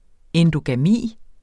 Udtale [ εndogaˈmiˀ ]